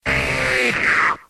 Cri de Chrysacier dans Pokémon X et Y.